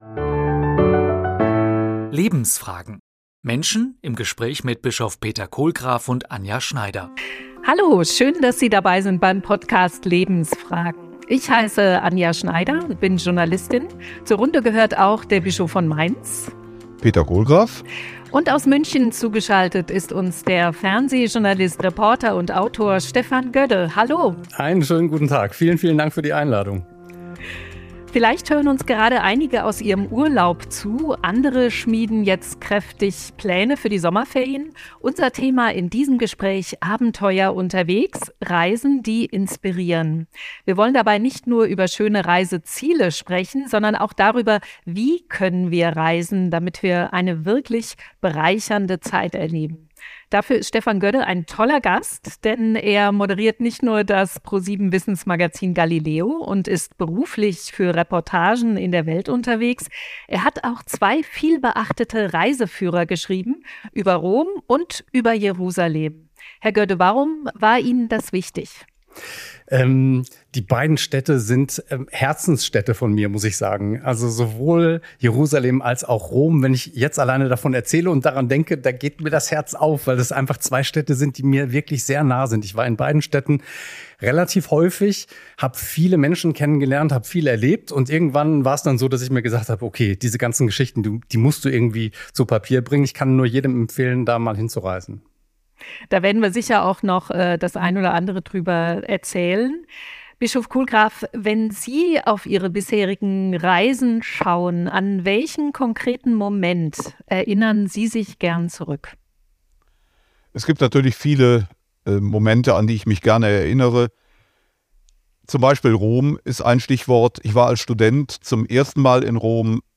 Zu Gast: Stefan Gödde.